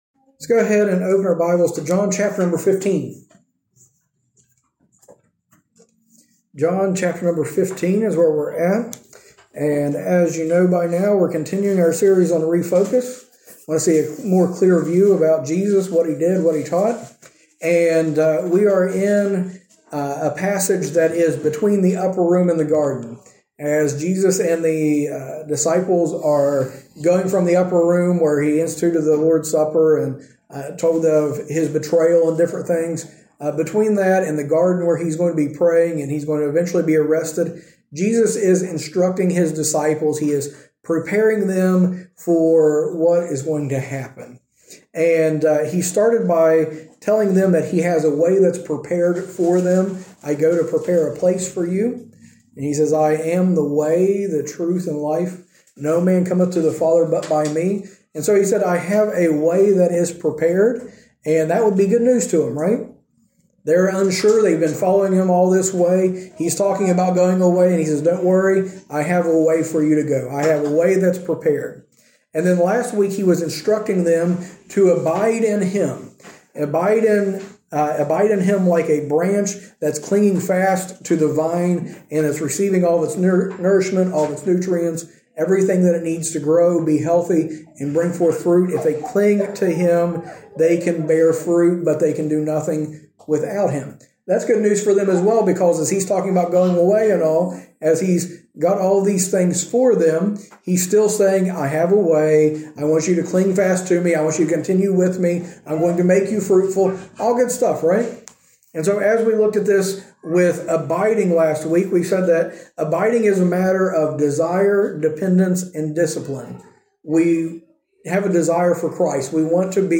A message from the series "Refocus on Christ."